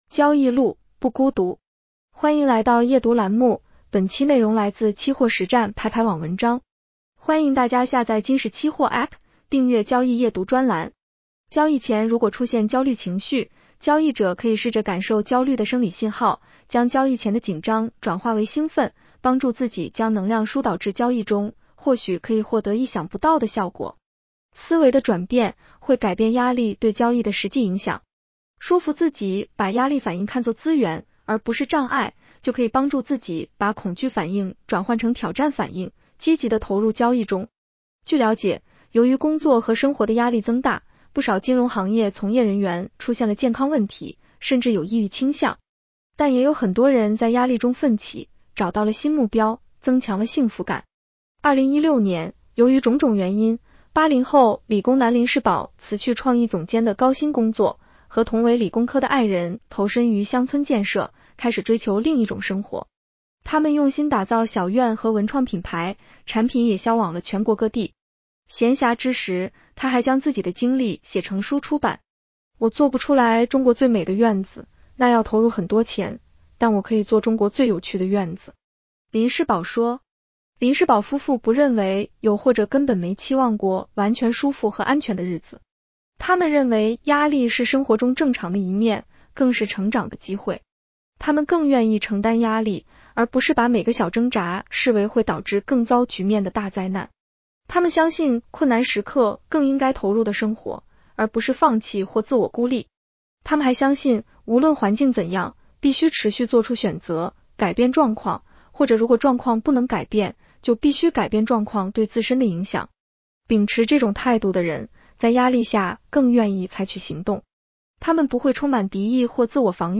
女声普通话版 下载mp3 交易前如果出现焦虑情绪，交易者可以试着感受焦虑的生理信号，将交易前的紧张转化为兴奋，帮助自己将能量疏导至交易中，或许可以获得意想不到的效果。